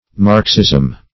Marxism \Marx"ism\, prop. n.